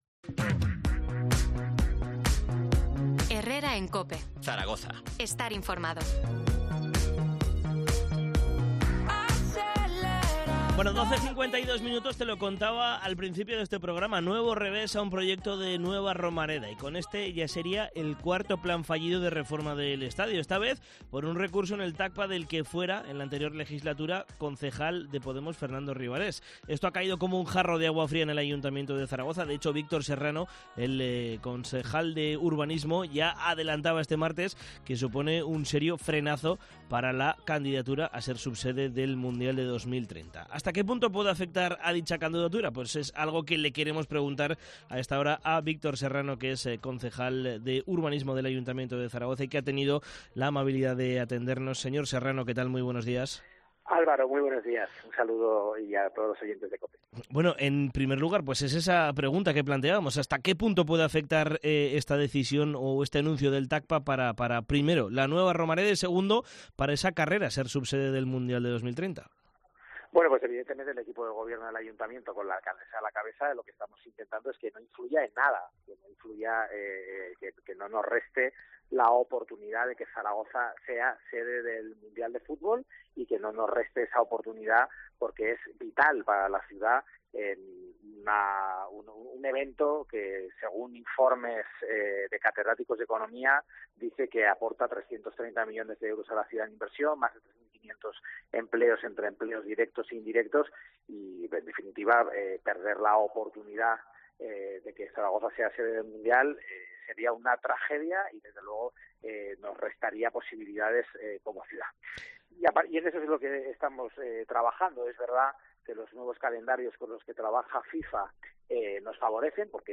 Entrevista a Víctor Serrano, consejero de Urbanismo del Ayuntamiento de Zaragoza